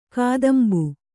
♪ kādambu